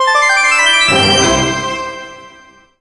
100coins_01.ogg